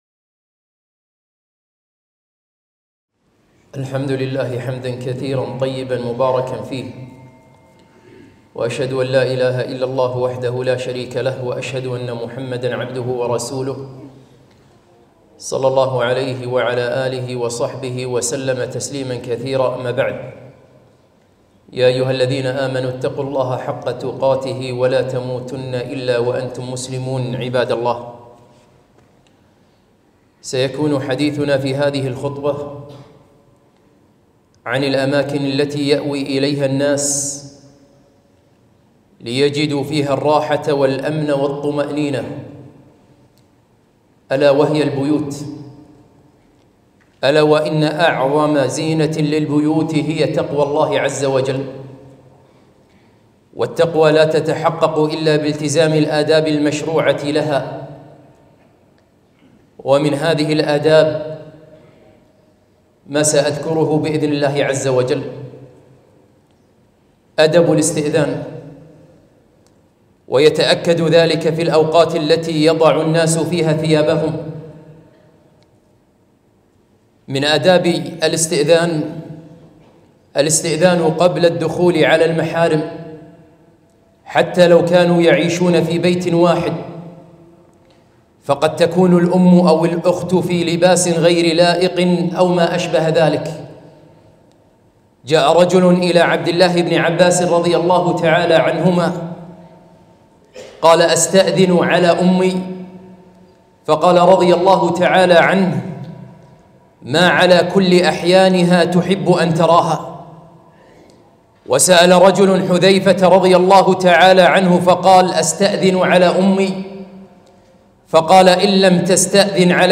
خطبة - آداب منزلية